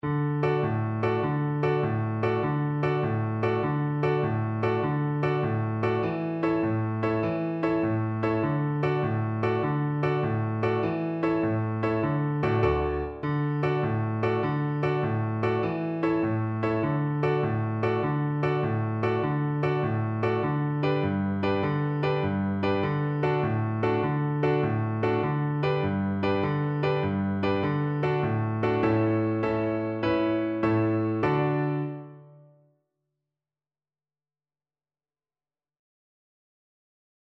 6/8 (View more 6/8 Music)
F#5-F#6
Allegro .=c.100 (View more music marked Allegro)